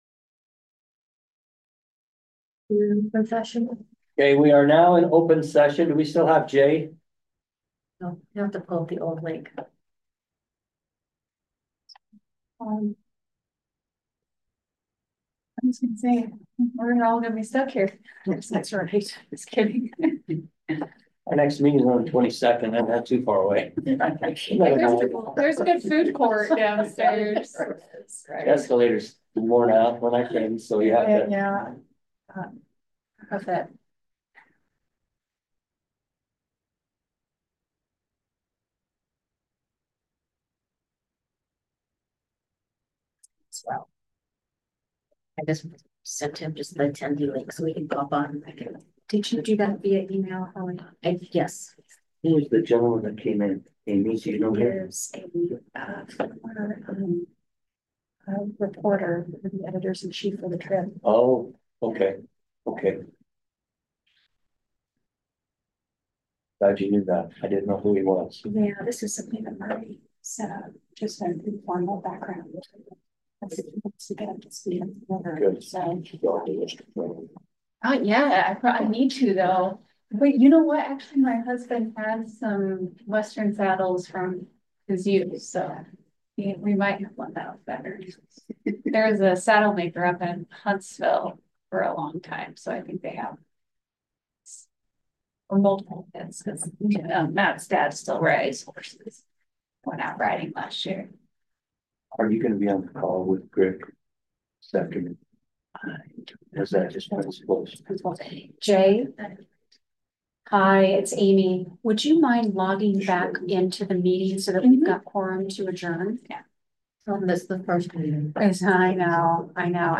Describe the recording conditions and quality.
1st Floor Training Room